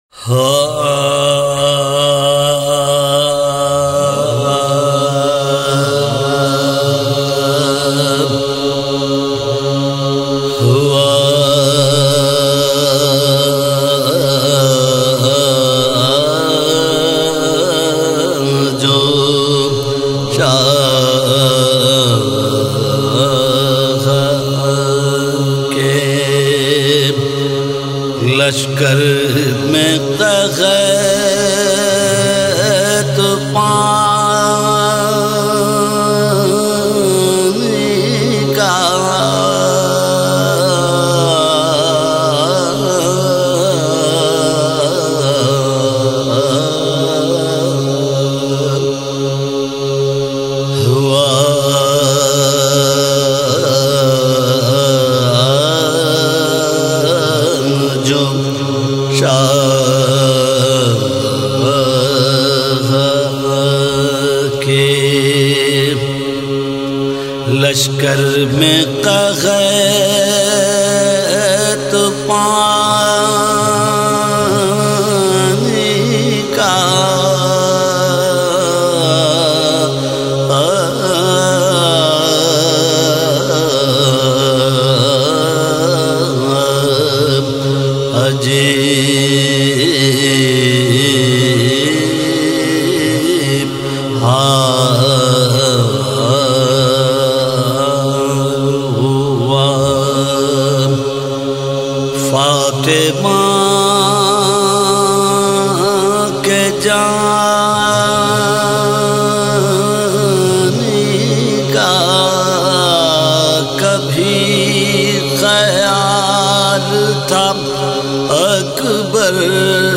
سوزوسلام اورمرثیے